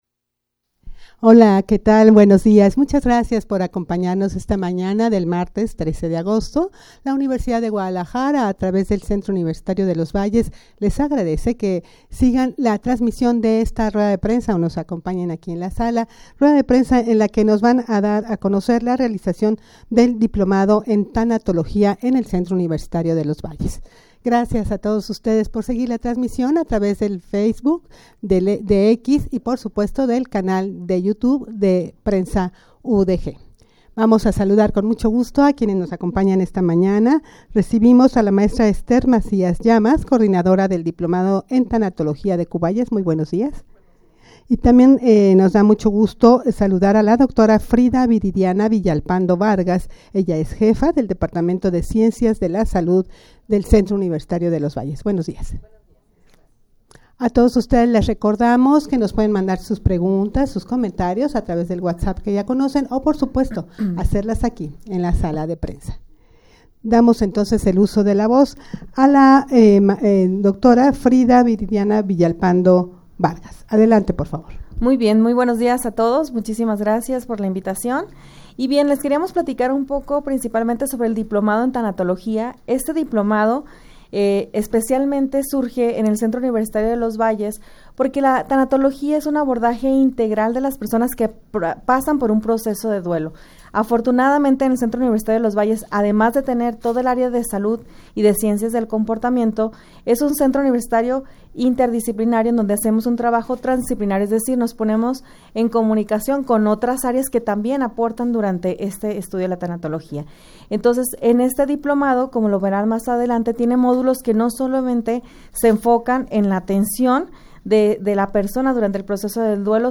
rueda-de-prensa-para-conocer-la-realizacion-del-diplomado-en-tanatologia-del-cuvalles.mp3